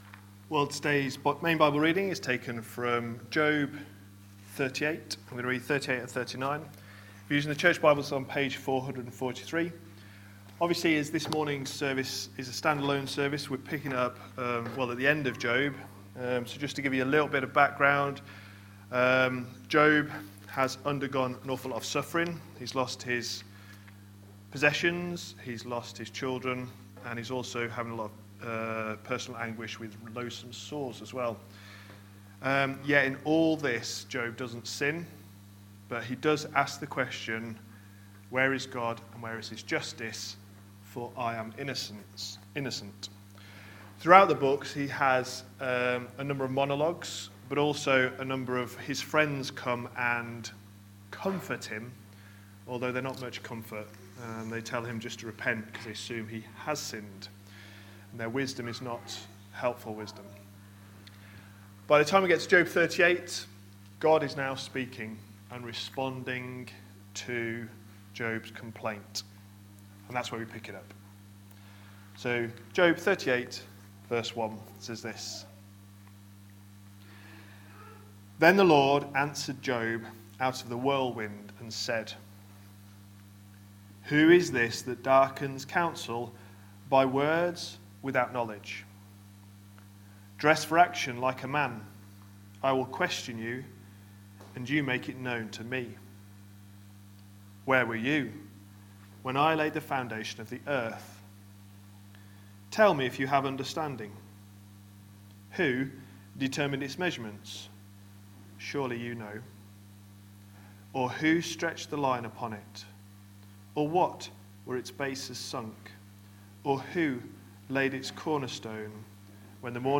A sermon preached on 29th December, 2019, as part of our New Year series.